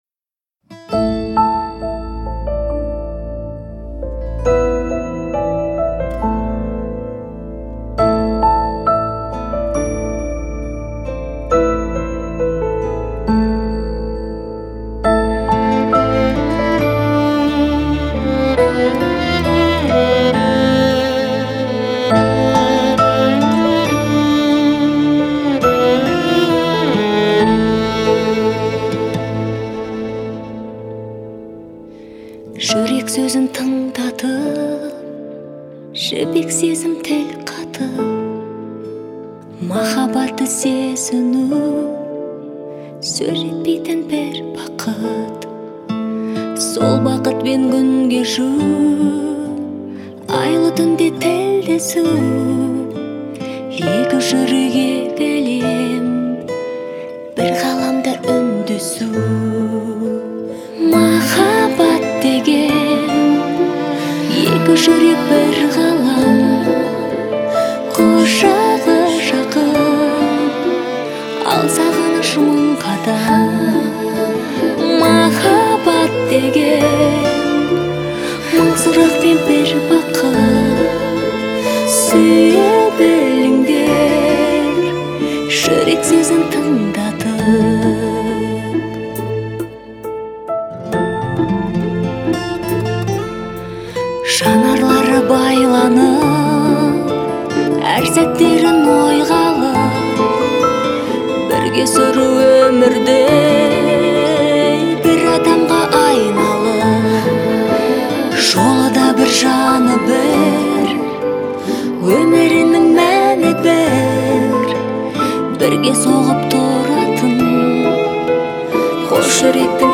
это трогательная песня в жанре казахского поп